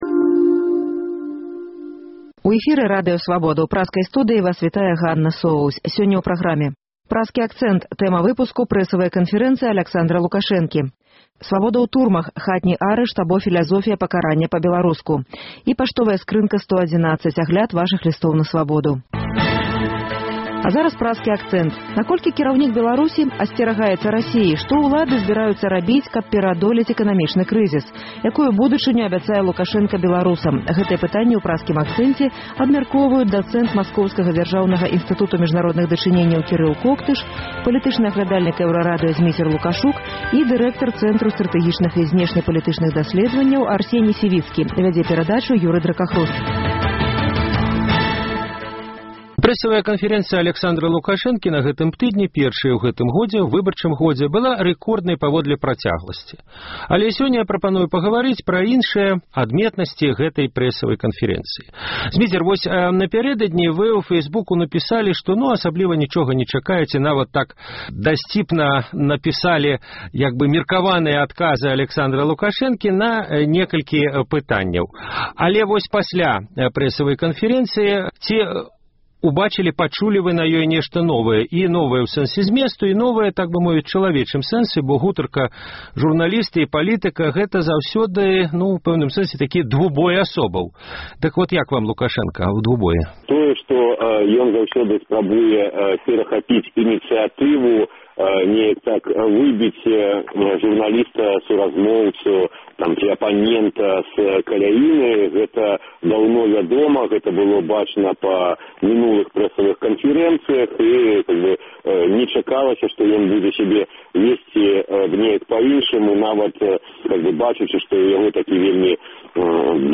Гэтыя пытаньні ў Праскім акцэнце абмяркоўваюць